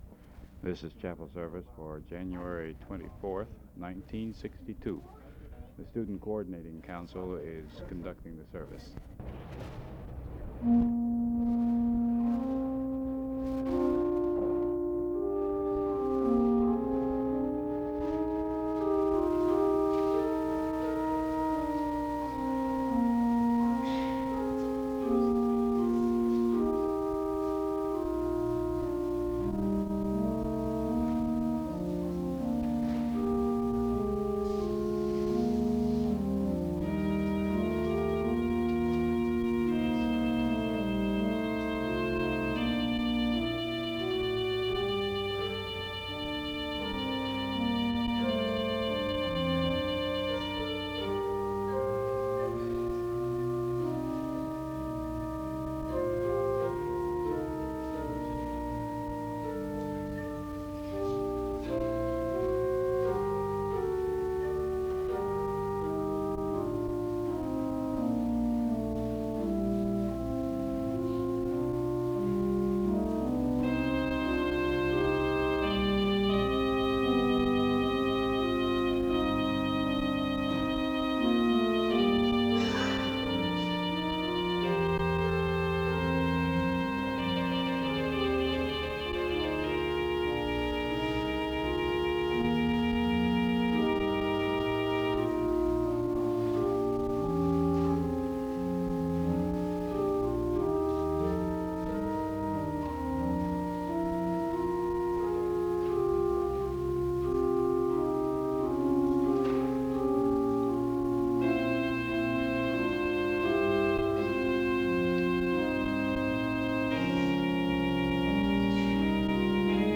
Download .mp3 Description This service was organized by the Student Coordinating Council and was the first chapel service of the new semester. Music is played from 0:09-5:03 as students file into the service. From 5:11-8:22 there are opening announcements, prayer, and a Scripture reading of Psalm 23.
There is a time of prayer and reflection from 15:55-20:30 as instrumental music is played.
Closing music plays from 20:59-27:13.